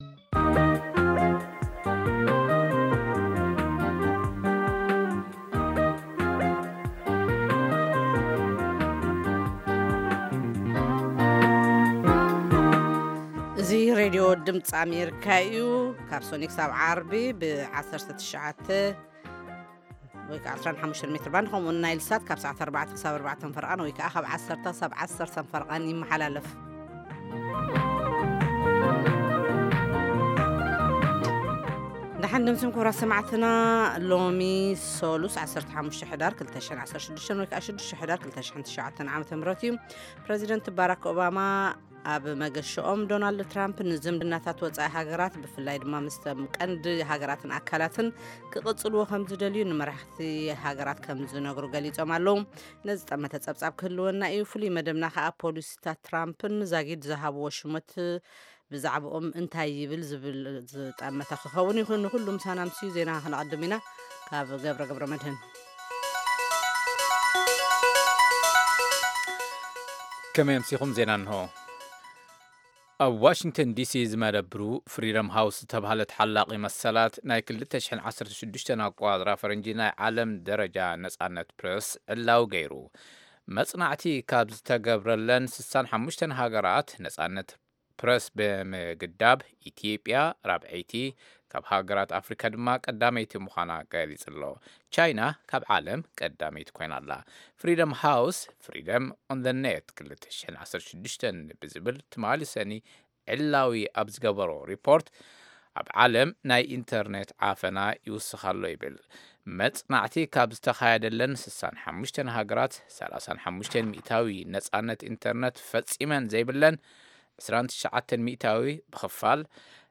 ፈነወ ትግርኛ ብናይ`ዚ መዓልቲ ዓበይቲ ዜና ይጅምር ። ካብ ኤርትራን ኢትዮጵያን ዝረኽቦም ቃለ-መጠይቓትን ሰሙናዊ መደባትን ድማ የስዕብ ። ሰሙናዊ መደባት ሰሉስ፡ ኤርትራውያን ኣብ ኣመሪካ/ ኣመሪካና